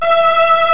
STRINGS  3.1.wav